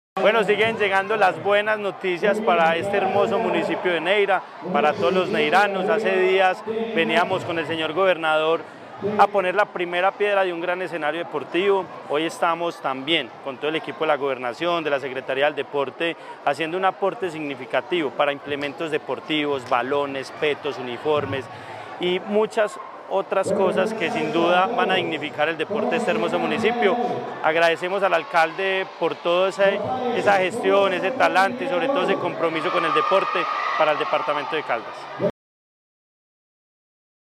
Andrés Duque Osorio, secretario de Deporte, Recreación y Actividad Física de Caldas.
andres-duque-osorio-secretario-de-drpotre-de-caldas.mp3